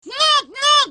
NOOT NOOT Sound Effect Button | Soundboardguys